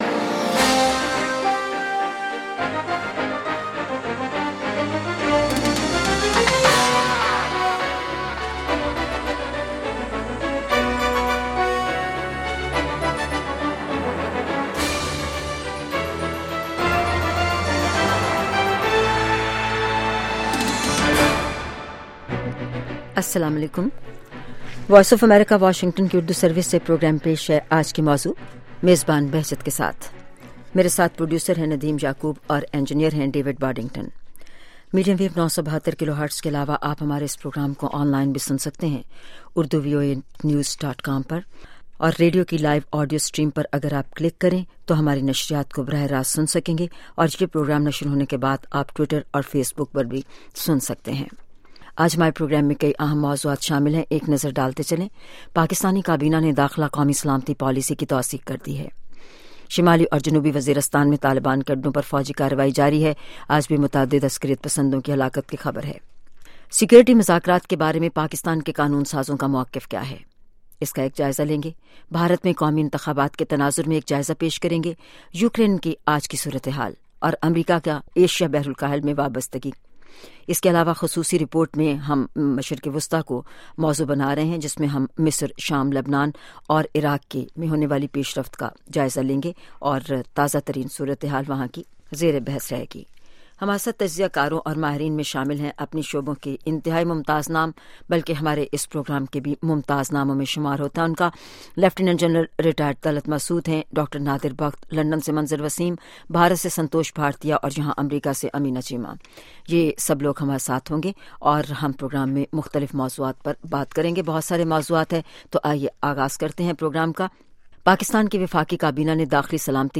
Sound Bites
Mideast Developments (Special Report)